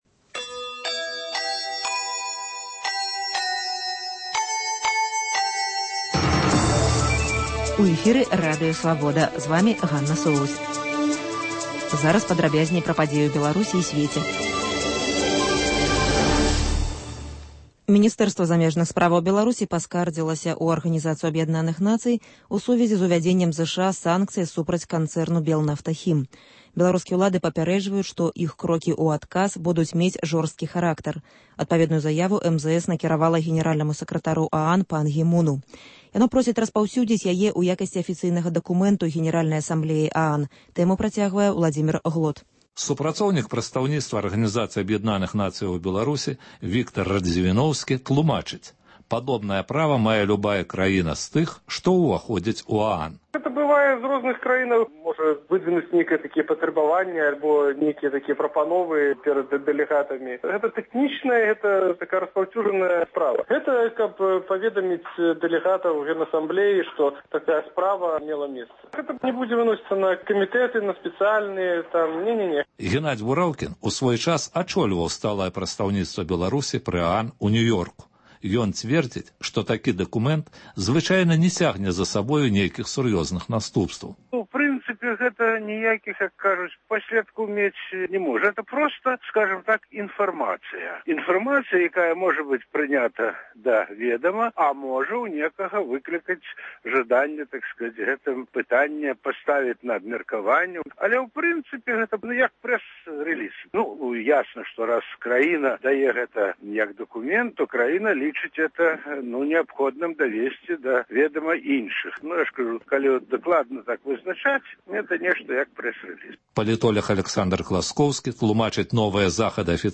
Паведамленьні нашых карэспандэнтаў, галасы слухачоў, апытаньні на вуліцах беларускіх гарадоў і мястэчак.